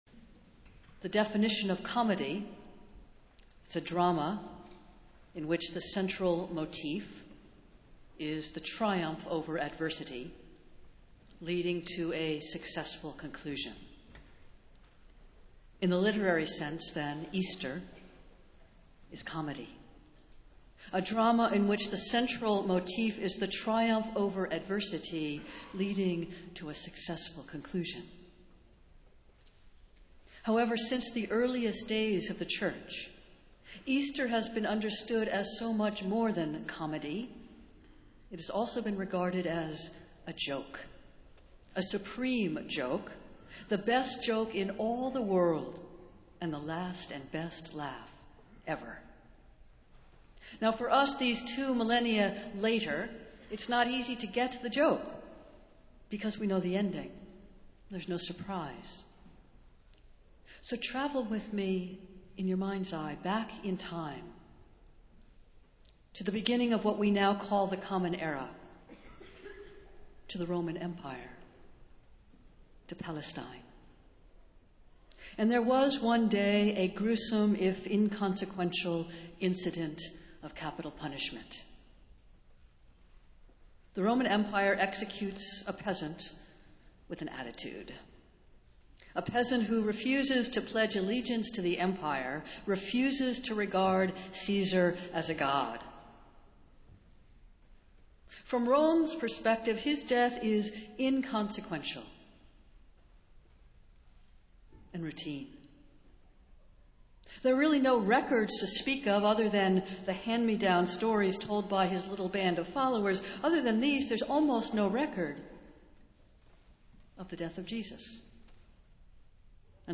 Festival Worship - Easter Sunday